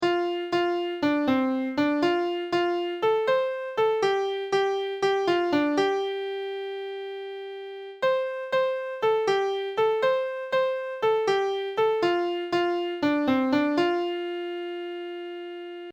1st time through: drum hands on thighs
Repeat the song a number of times, getting faster